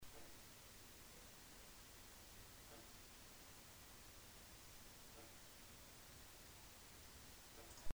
I have made a quick recording of the background hissing/noise. i have made this sample a little louder so its easy to hear.:o